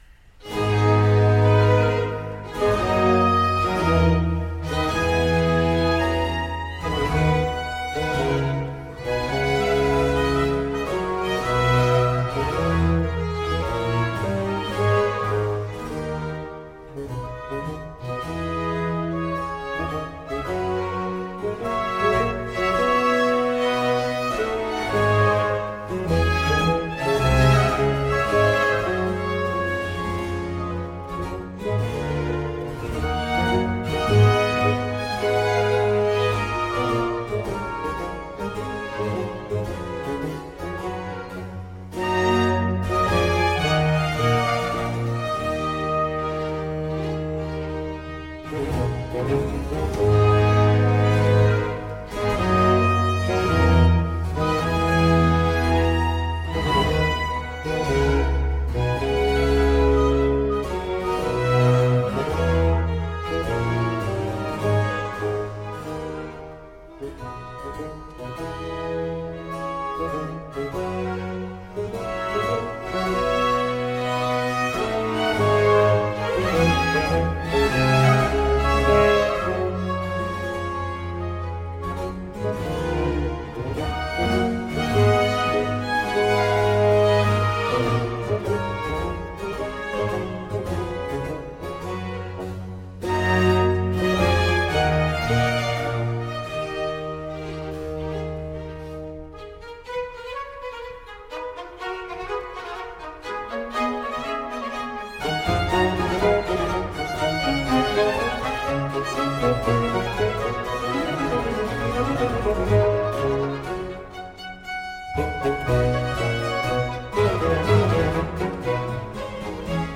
Wind Quartet
Oboe 1 Oboe 2 Cor Anglais Oboe dAmore Bassoon
Style: Classical